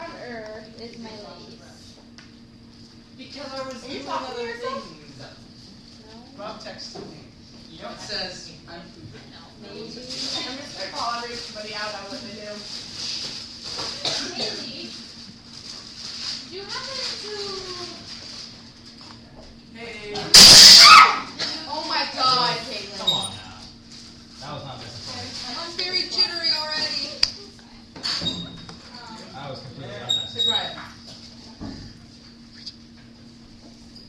Field Recording 5
SOUND CLIP: Design Room, Lowe Hall LOCATION: Design Room, Lowe Hall SOUNDS HEARD: people talking, T-square falling, scream, hum from computer/printer